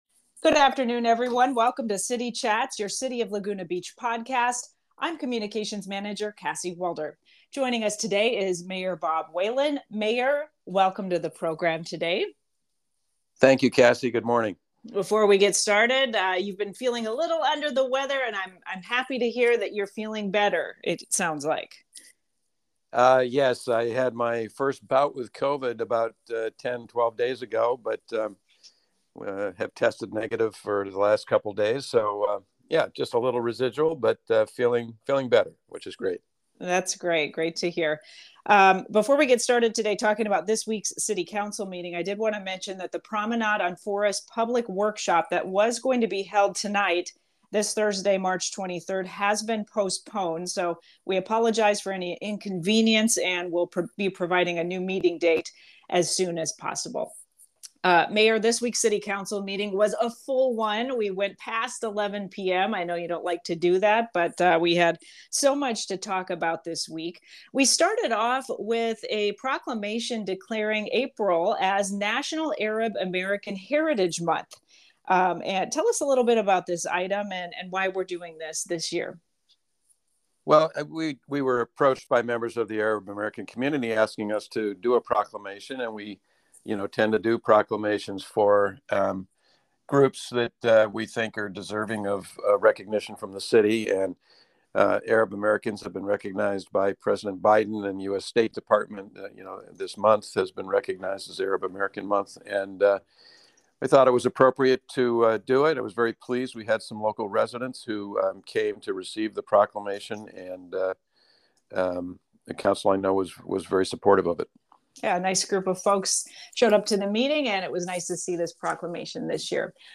This week, Mayor Whalen talks about all of the items discussed during this week's City Council meeting, including why the bluff top remodel item was delayed to be discussed at a future City Council meeting.